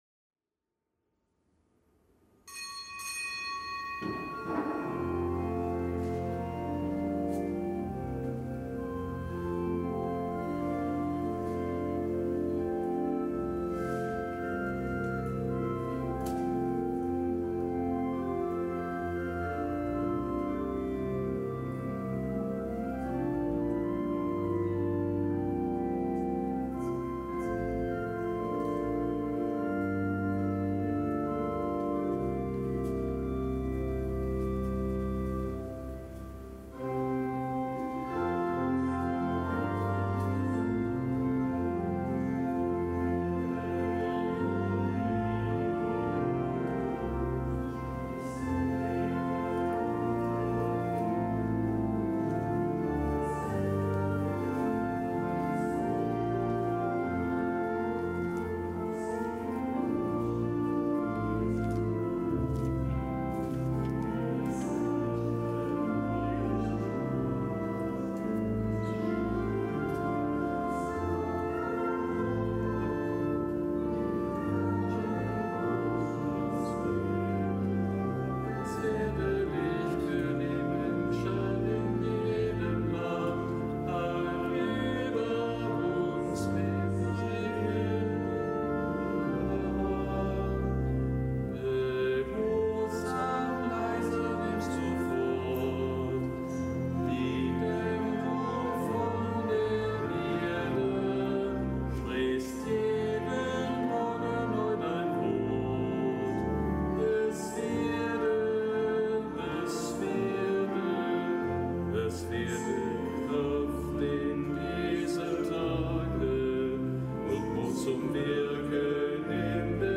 Kapitelsmesse am Mittwoch der vierzehnten Woche im Jahreskreis
Kapitelsmesse aus dem Kölner Dom am Mittwoch der vierzehnten Woche im Jahreskreis, nichtgebotener Gedenktag des Heiligen Agilolf, Bischof von Köln (DK); des Heiligen Augustinus Zhao Rong, Priester,